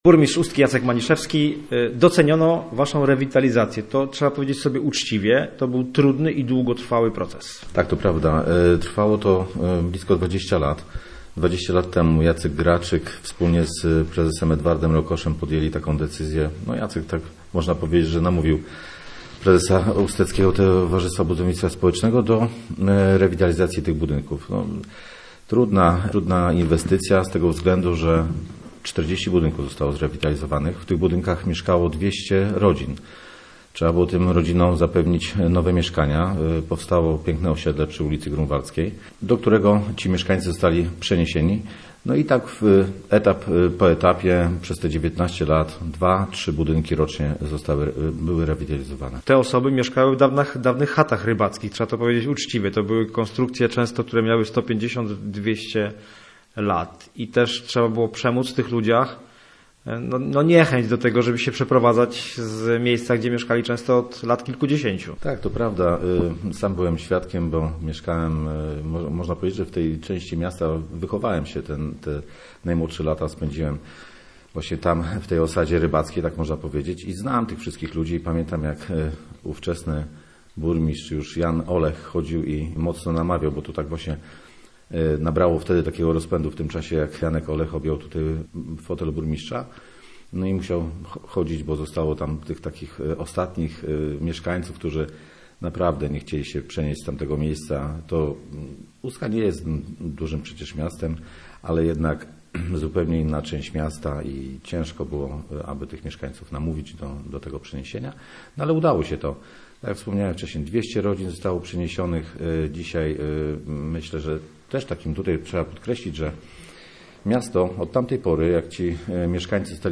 Powstało nowe osiedle: cztery budynki, do których stopniowo przenosili się mieszkańcy – mówi burmistrz Ustki, Jacek Maniszewski.